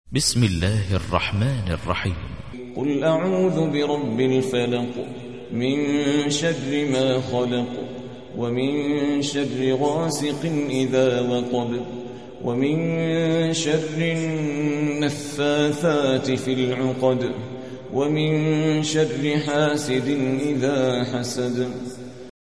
113. سورة الفلق / القارئ